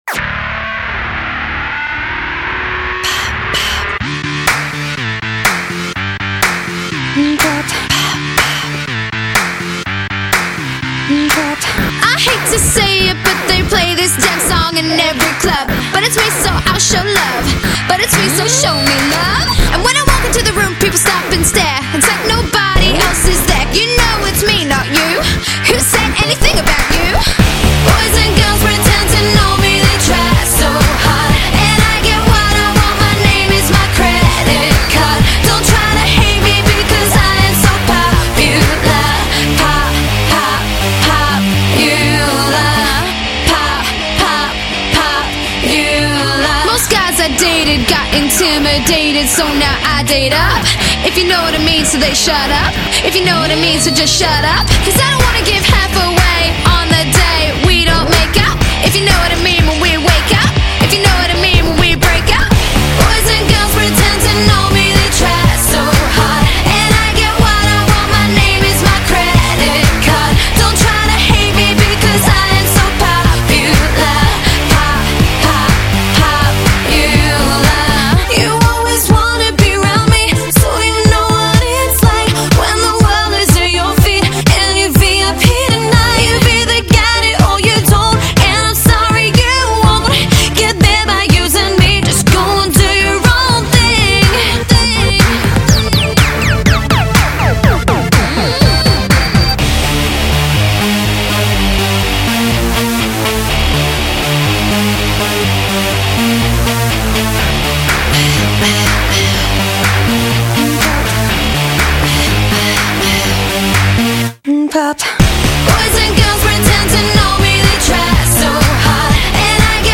Australian pop band